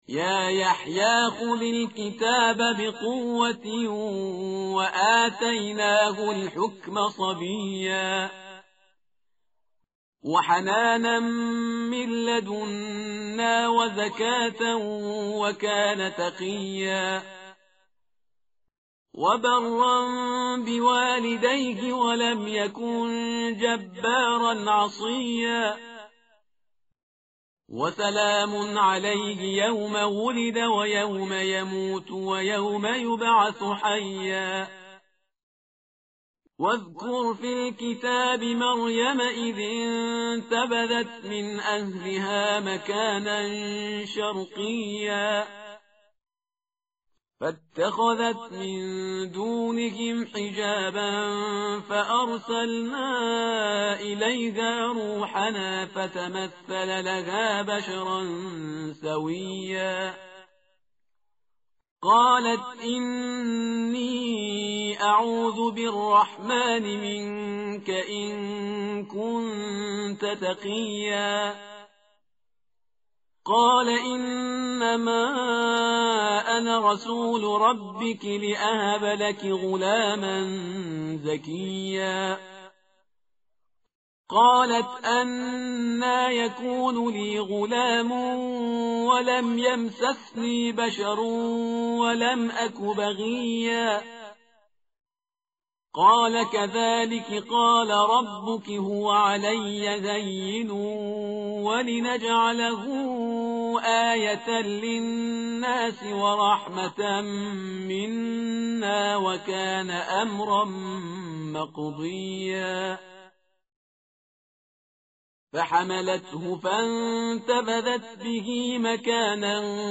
متن قرآن همراه باتلاوت قرآن و ترجمه
tartil_parhizgar_page_306.mp3